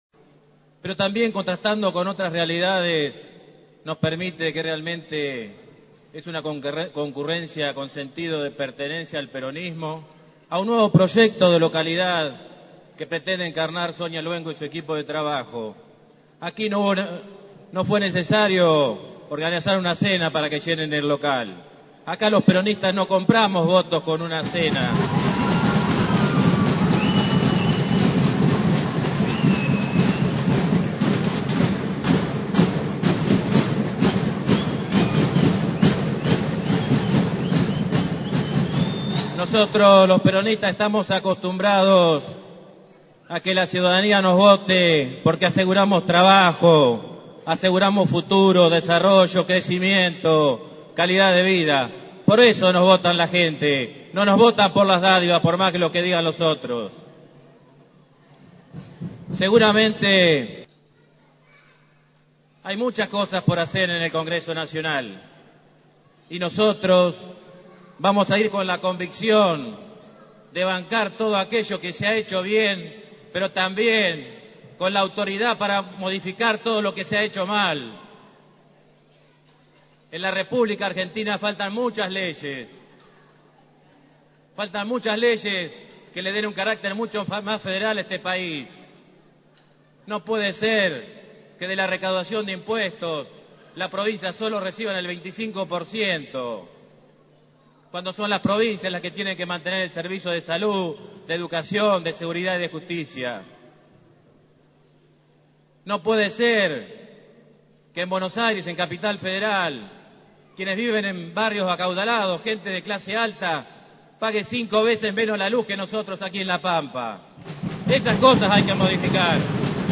El candidato a diputado nacional, Sergio Ziliotto, agradeció la presencia de militantes y vecinos